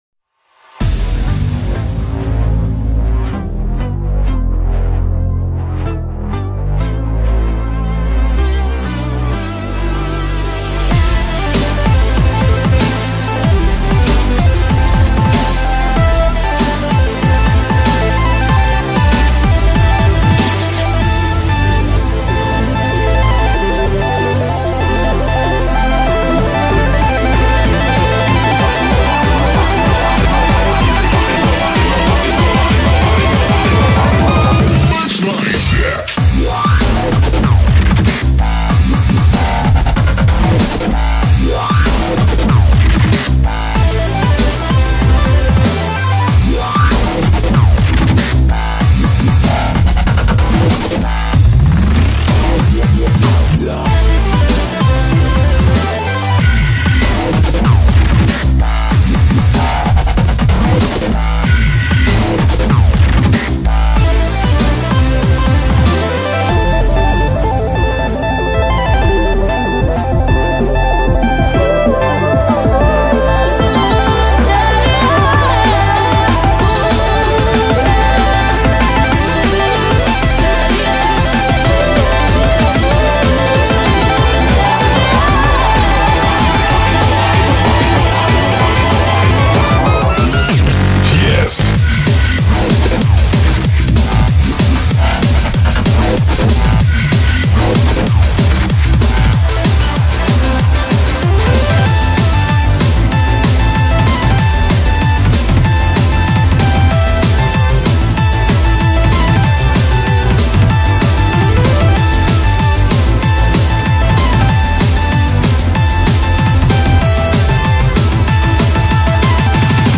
BPM95-190
Audio QualityPerfect (Low Quality)